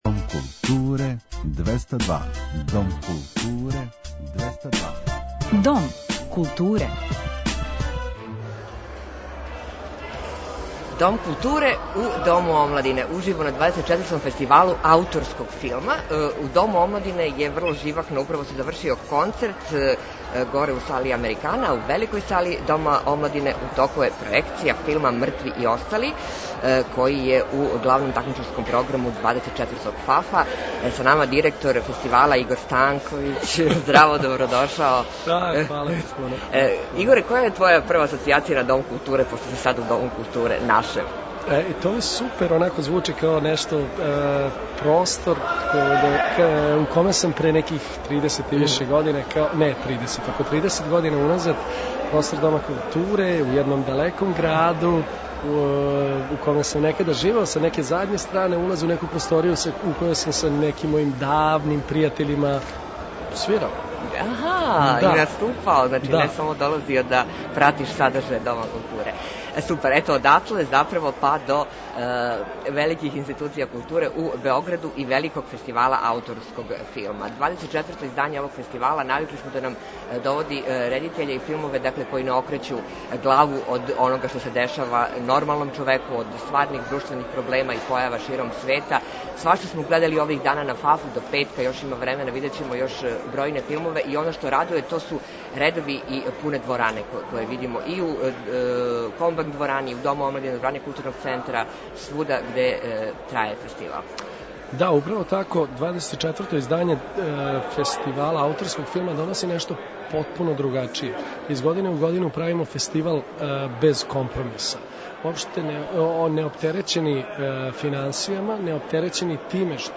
Дом културе уживо на Фестивалу ауторског филма